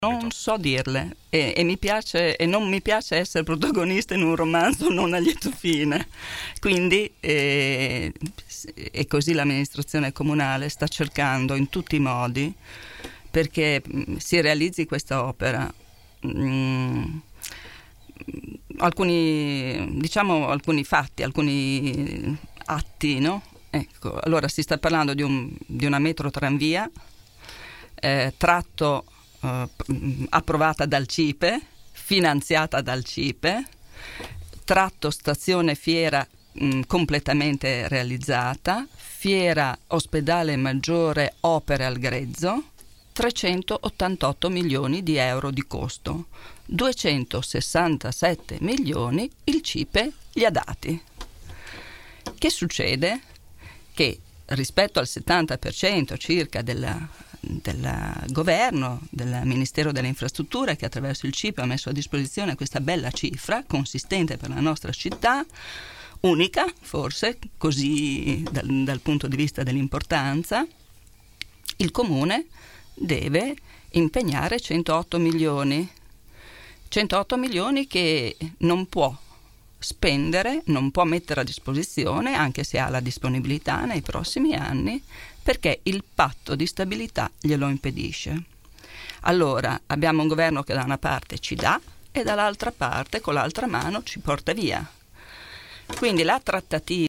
“C’è l’obiettivo di aumentarle perché i mezzi pubblici possano viaggiare più velocemente” ha fatto sapere questa mattina durante il microfono aperto nei nostri studi l’assessore al Traffico e alla Mobilità, Simonetta Saliera.